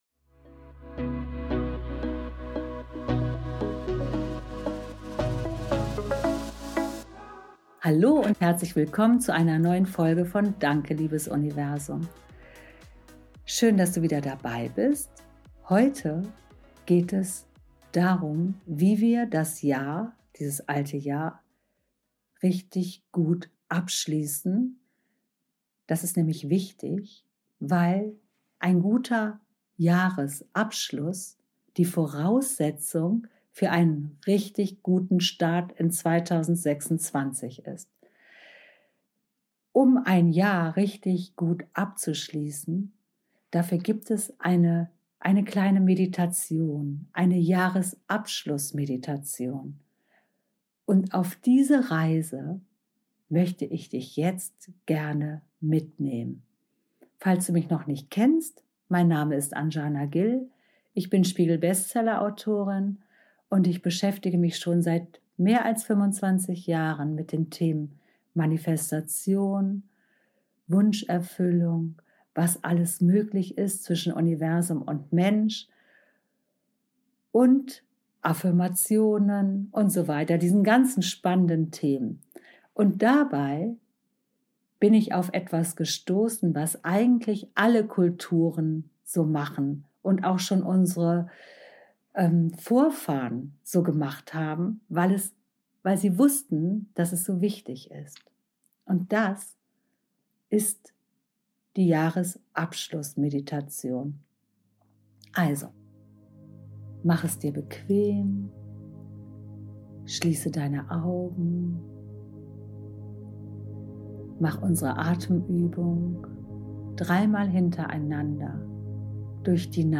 In dieser Folge nehme ich dich mit auf eine besondere Jahresabschluss-Meditation, mit der du das alte Jahr voller Frieden und Dankbarkeit loslässt. Wir gehen gemeinsam Monat für Monat durch dein 2025, schauen hin, würdigen, was war – und schließen alles bewusst ab.